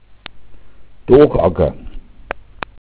Lateinische Sprachrelikte im bayerischen Dialekt, Flurnamen
Originaltext: Auf die Bodenart weisen die Bezeichnungen Tagacker, zu ahd. daha Lehm, Stoffsammlung: Mundart: doch_agga Quelle Buck: Dach, mhd. dâhe, Dohle.